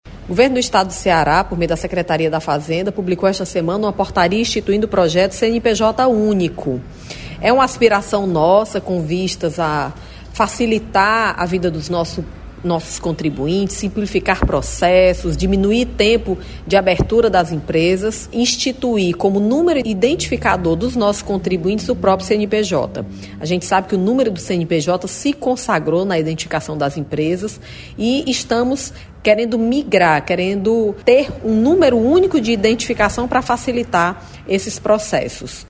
O programa vai se caracterizar por simplificar e facilitar os processos de inscrição dos contribuintes dos tributos estaduais e de alteração cadastral; e unificar informações relativas aos contribuintes estaduais, possibilitando compartilhar, em tempo real, com a Receita Federal, como explica a secretária da Fazenda, Fernanda Pacobahyba.